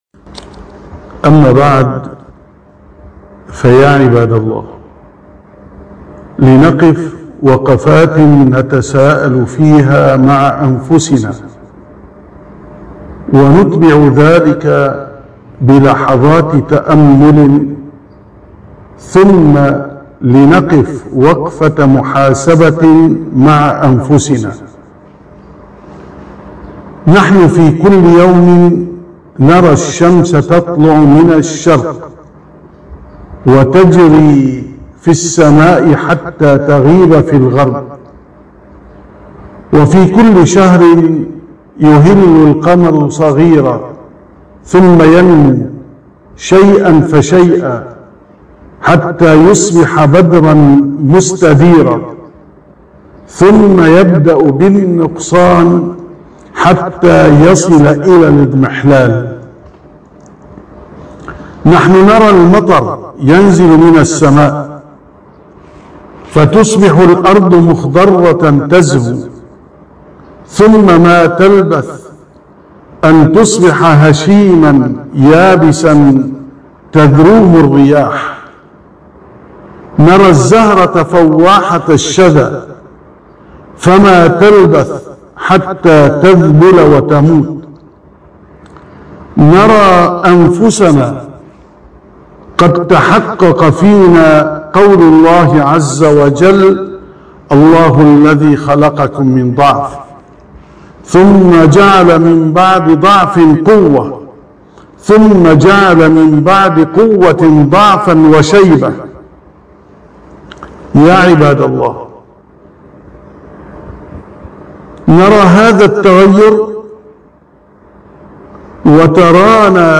944ـ خطبة الجمعة: وقفة تساءل وتأمل